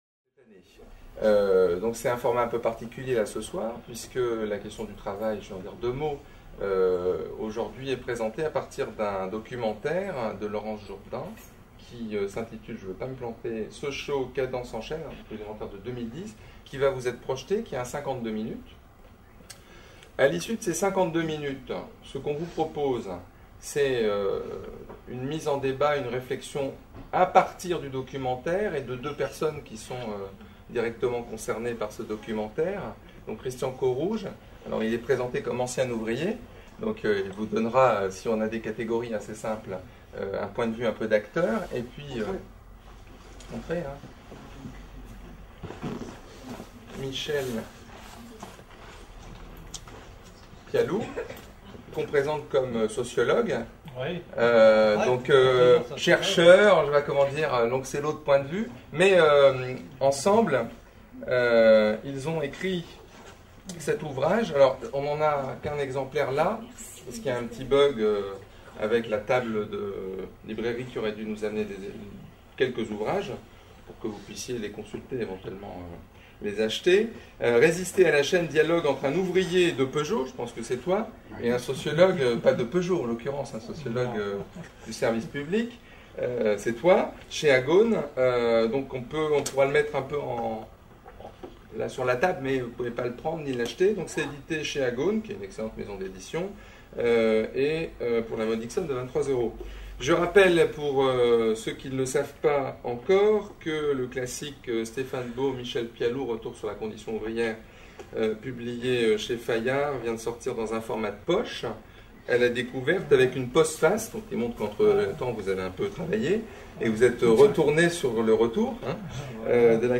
Cet entretien a suivi la diffusion du film de Laurence Jourdan, Sochaux, cadences en chaînes, 2010 (production INA, 53 mn).